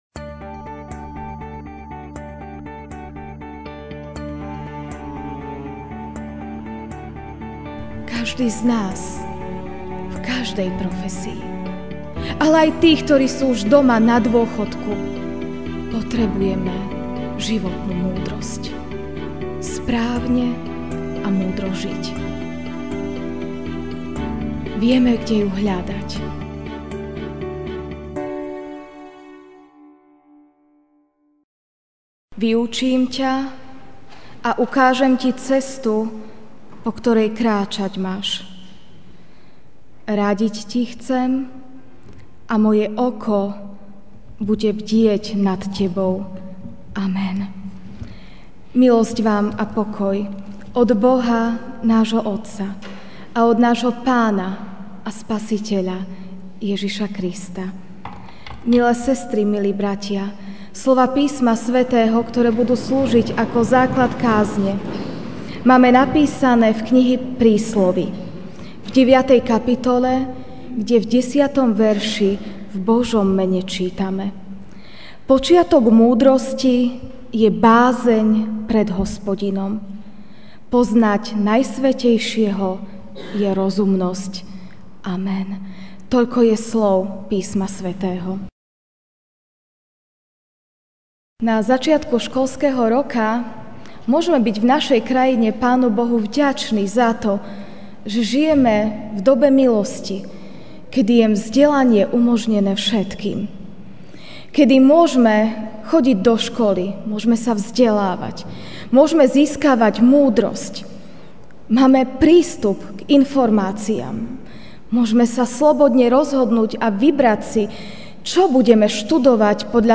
sep 04, 2016 Múdrosť MP3 SUBSCRIBE on iTunes(Podcast) Notes Sermons in this Series Ranná kázeň: (Pr. 9, 10) Počiatok múdrosti je bázeň pred Hospodinom, poznať Najsvätejšieho je rozumnosť.